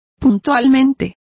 Complete with pronunciation of the translation of promptly.